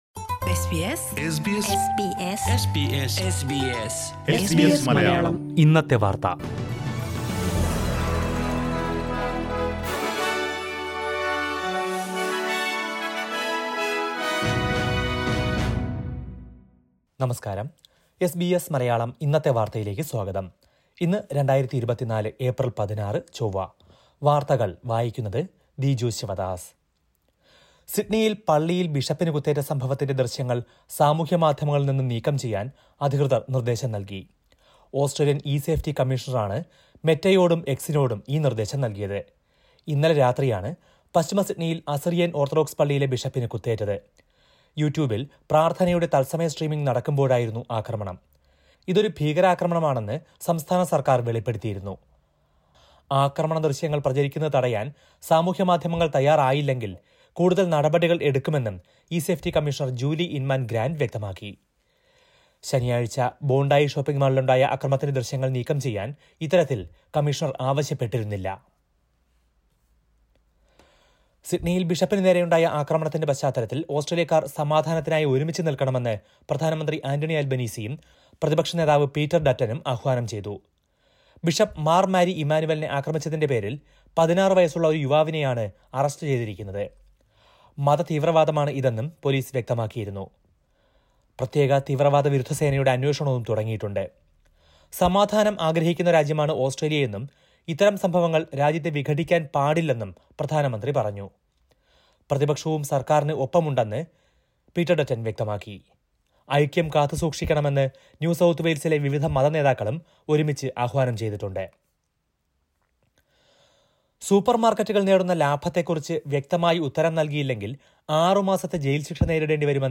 2024 ഏപ്രില്‍ 16ലെ ഓസ്‌ട്രേലിയയിലെ ഏറ്റവും പ്രധാന വാര്‍ത്തകള്‍ കേള്‍ക്കാം...